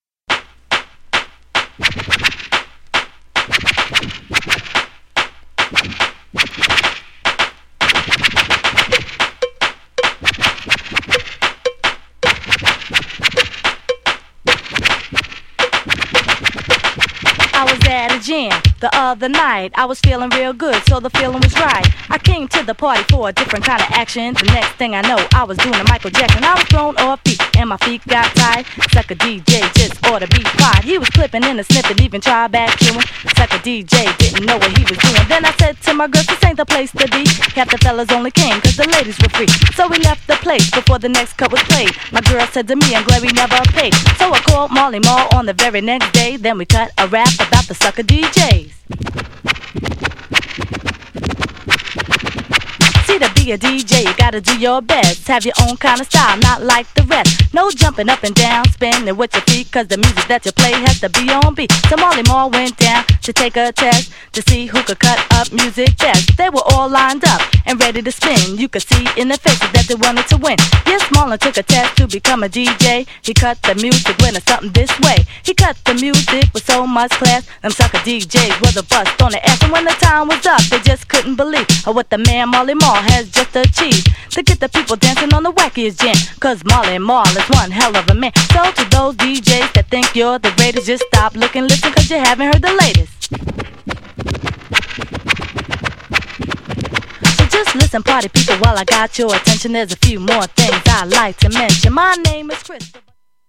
GENRE Hip Hop
BPM 106〜110BPM
OLD_SCHOOL # PARTY_DISCO_RAP
ダンサブルHIPHOP # フィーメイルRAP